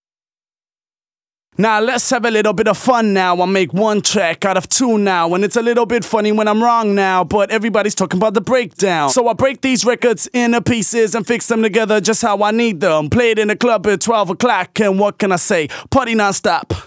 VTDS2 Song Kit 128 BPM Rap 1 Out Of 2